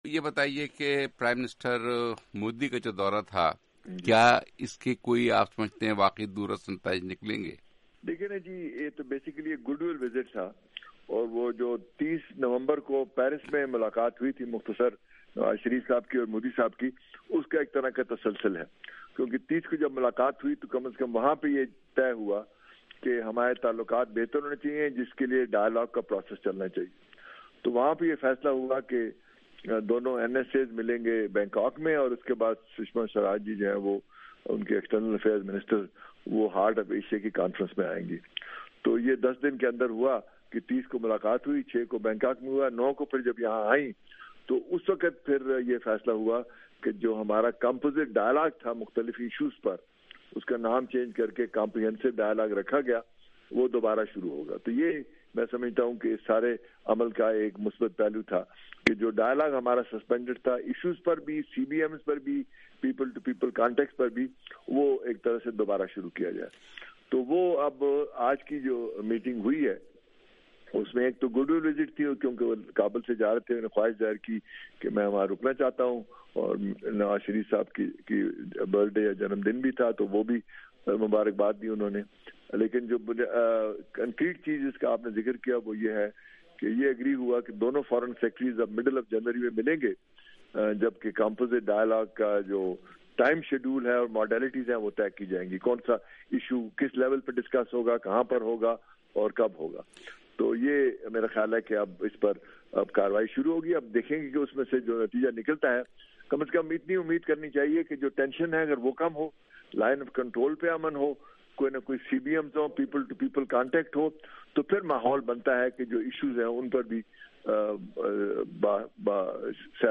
آڈیو انٹرویو مشیر خارجہ سرتاج عزیز